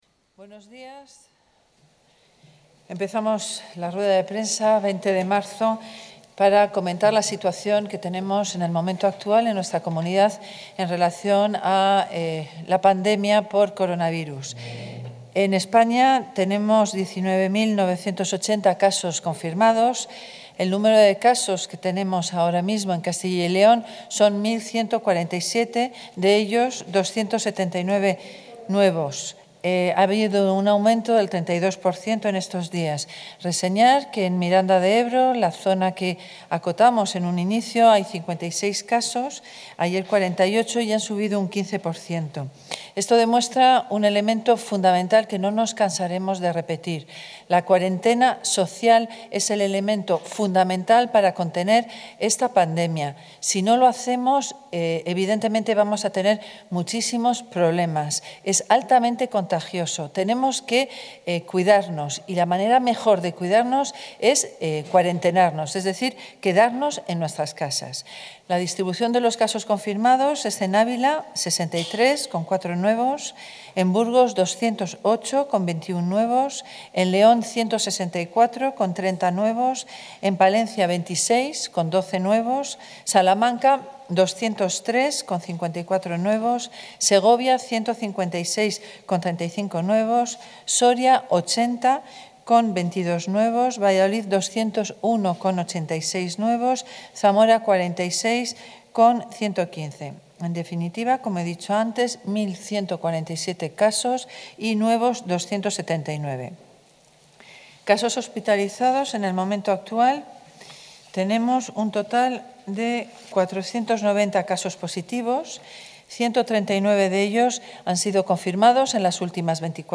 Material audiovisual de la rueda de prensa de las consejeras de Sanidad y de de Familia e Igualdad de Oportunidades en en relación al COVID-19 | Comunicación | Junta de Castilla y León
Las consejeras de Sanidad, Verónica Casado, y de Familia e Igualdad de Oportunidades, Isabel Blanco, han comparecido hoy en rueda de prensa para informar de la situación en la Comunidad en relación al COVID-19.